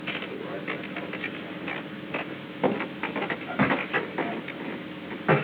Secret White House Tapes
Conversation No. 442-71
Location: Executive Office Building
The President met with an unknown person.